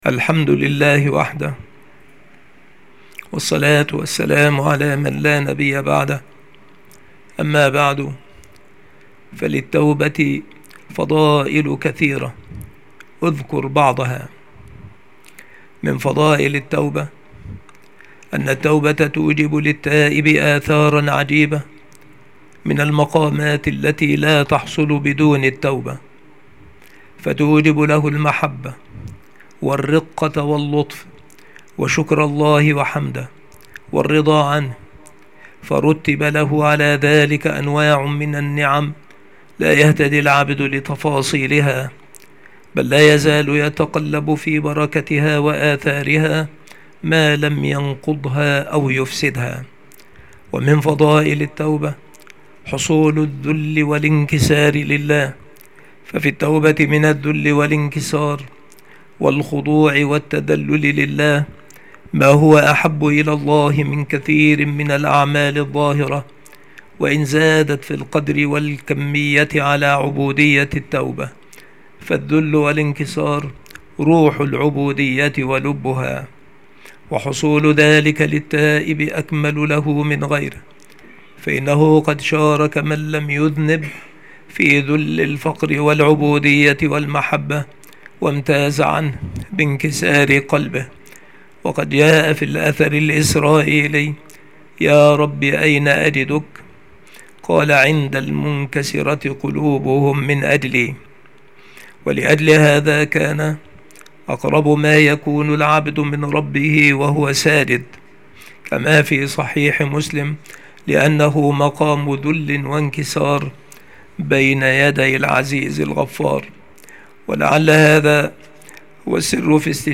مكان إلقاء هذه المحاضرة المكتبة - سبك الأحد - أشمون - محافظة المنوفية - مصر